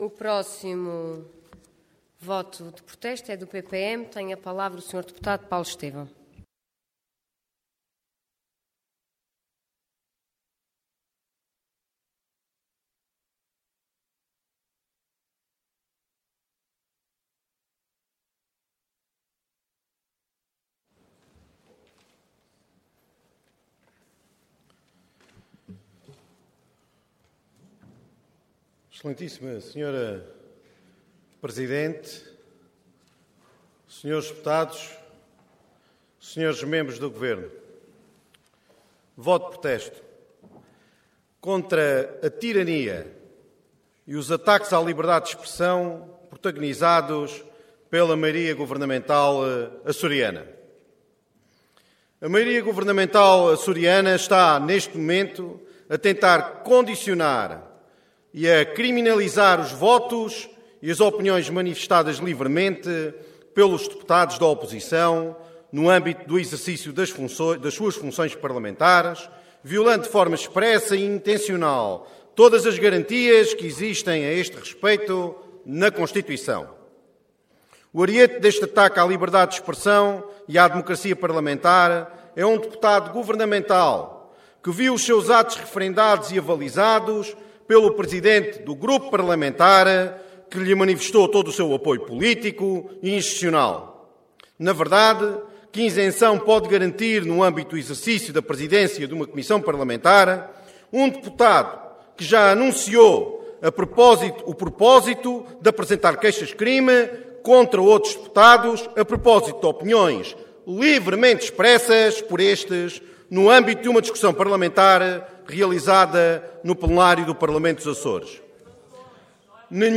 Intervenção Voto de Protesto Orador Paulo Estêvão Cargo Deputado Entidade PPM